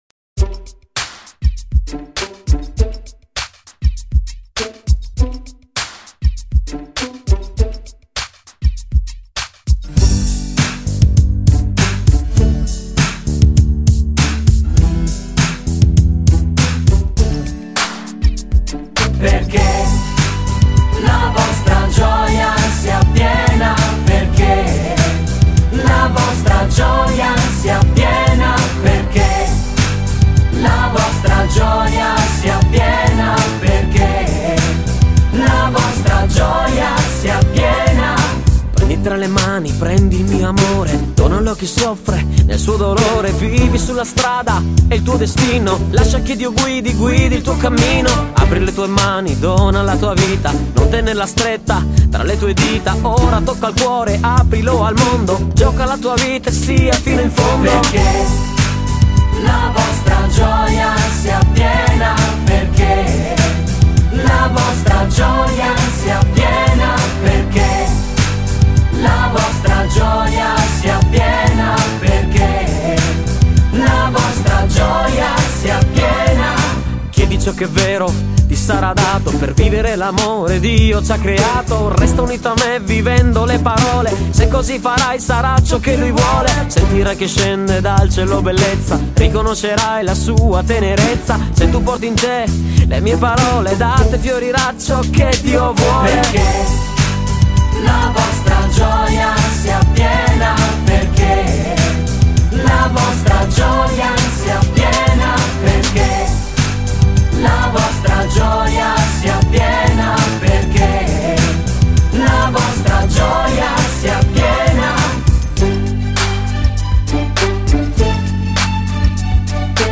la canzone inno